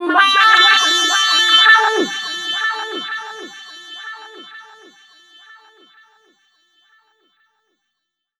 VOCODE FX1-L.wav